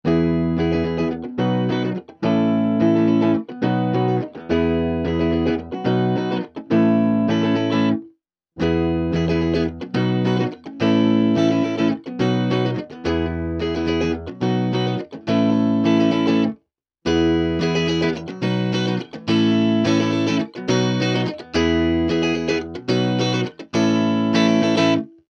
A clean boost and overdrive with excellent tone control.
Clip 1: 0% Tone, 50% Tone, 100% Tone
guitar - effect - cabinet simulator - sound card